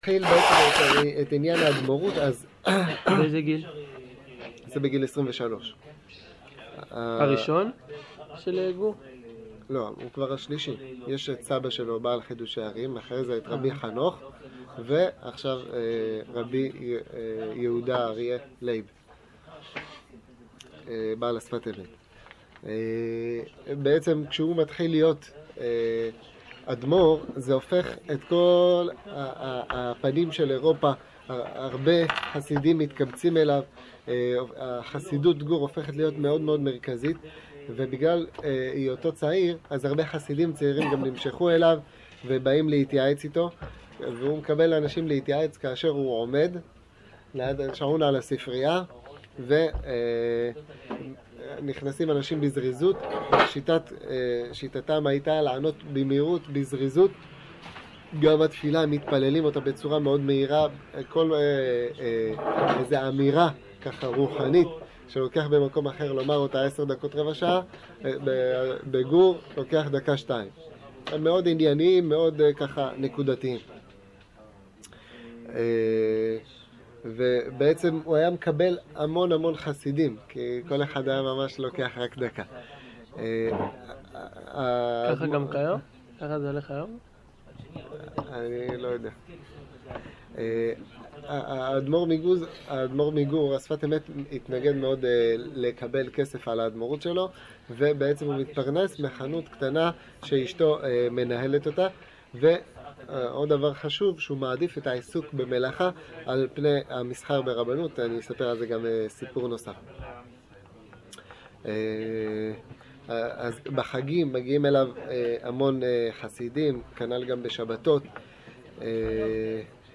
שיעור שפת אמת